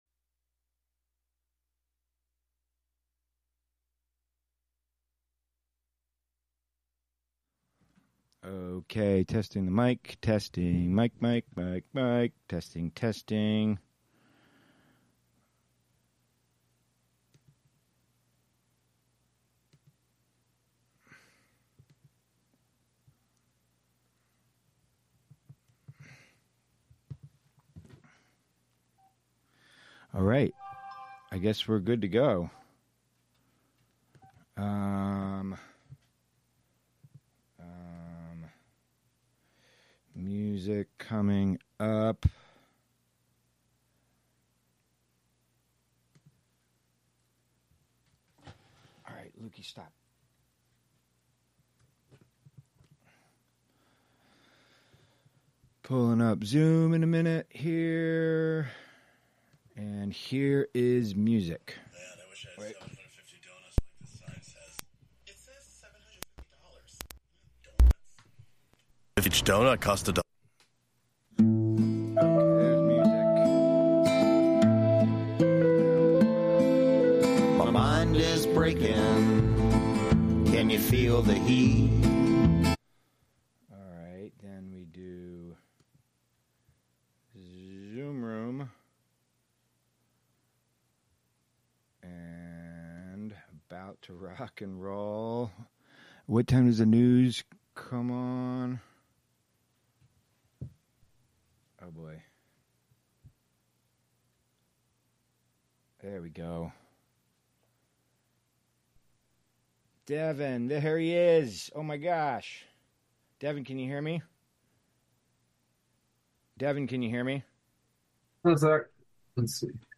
Prime Jive: Monday Afternoon Show- Live from Housatonic, MA (Audio)